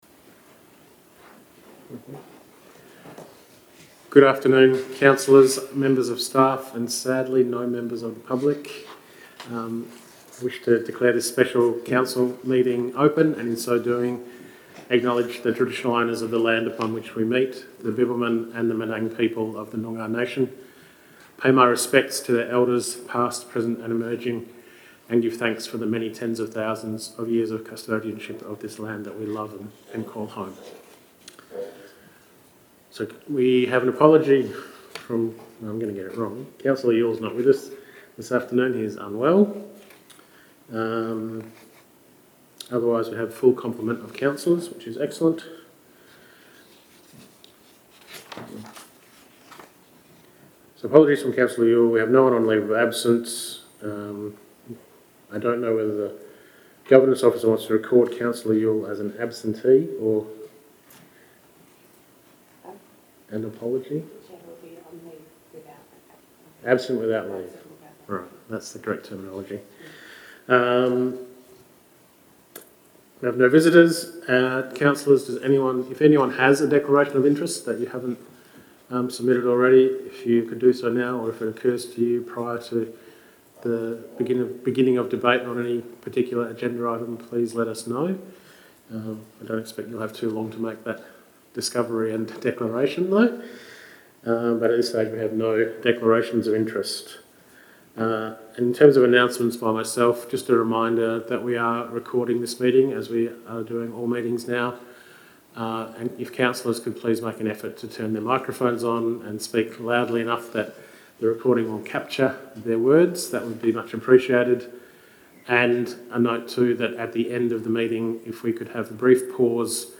11 June 2024 - Special Meeting of Council » Shire of Denmark